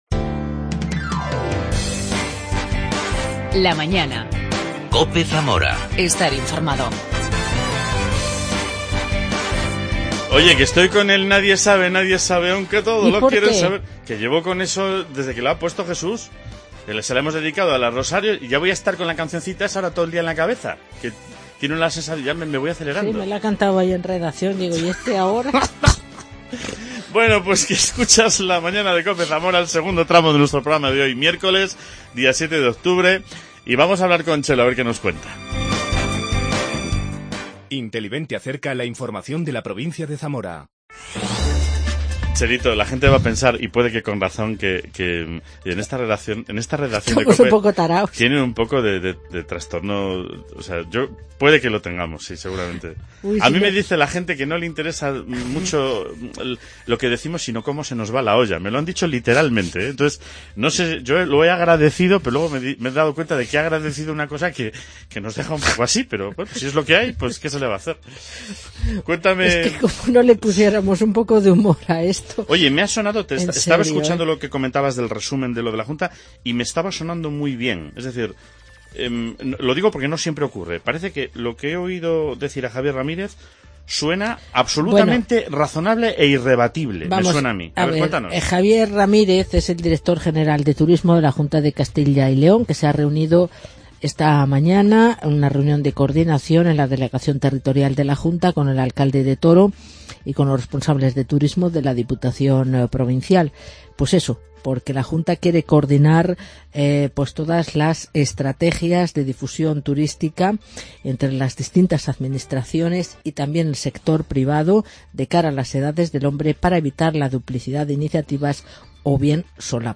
El viceportavoz del Grupo Popular en el Ayuntamiento de Zamora, Victor López, habla sobre la moción que se presentará en el próximo Pleno para pedir la liberación del alcalde metropolitano de Caracas, Antonio Ledezma.